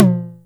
909 H Tom 1.wav